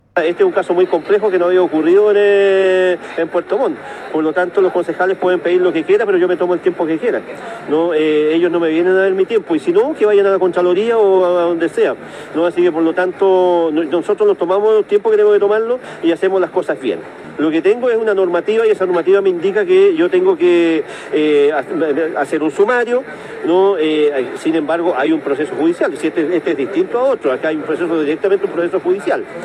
Igualmente se le preguntó al alcalde de Puerto Montt por qué el proceso se ha extendido más de 5 meses, cuando la normativa establece que estos procesos deberían concluir en 25 días.